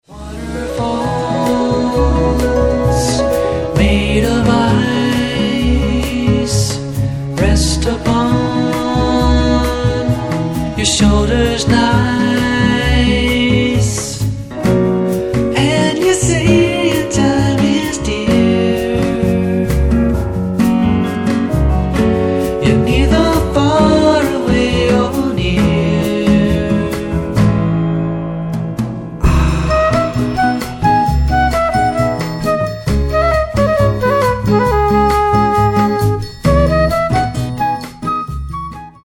極上のメロウ・ヴォーカル・ボッサ